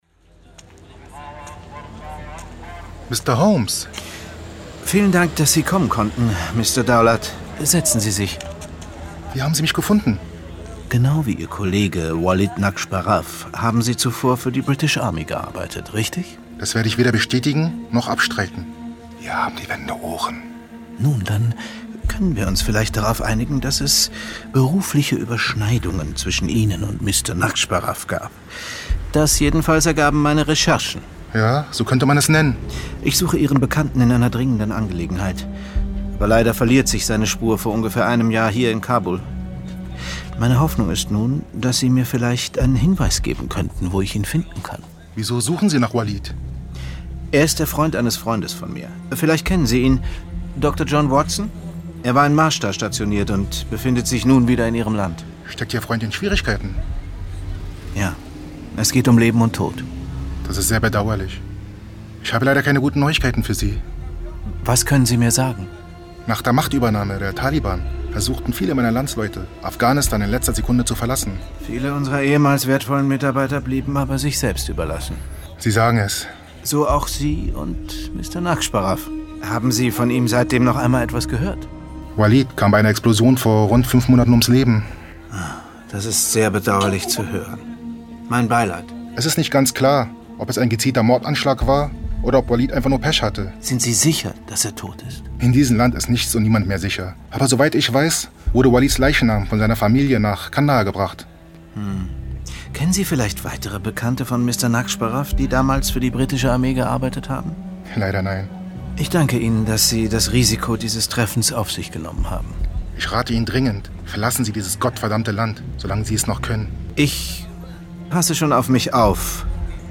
Hörspiel mit Johann von Bülow, Florian Lukas, Stefan Kaminski u.v.a. (2 CDs)
Johann von Bülow, Florian Lukas, Stefan Kaminski (Sprecher)